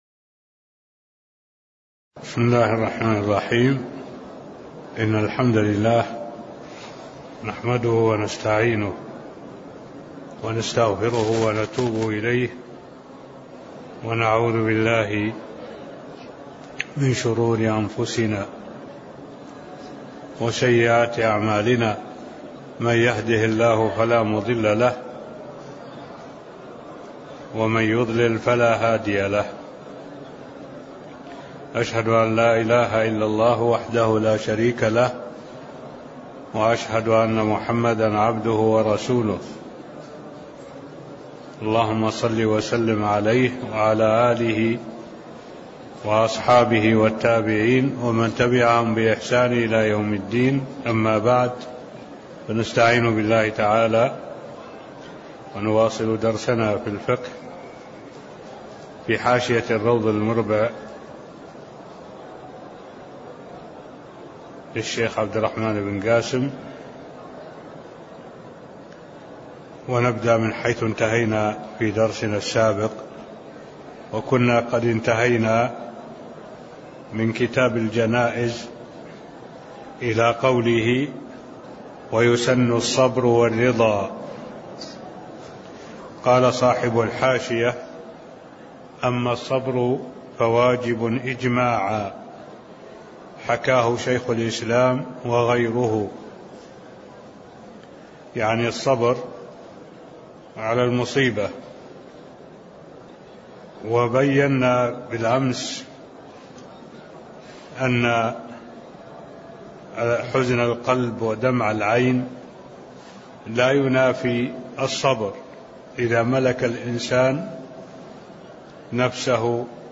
تاريخ النشر ٢ ربيع الأول ١٤٢٩ هـ المكان: المسجد النبوي الشيخ: معالي الشيخ الدكتور صالح بن عبد الله العبود معالي الشيخ الدكتور صالح بن عبد الله العبود يسن الصبر والرضى (006)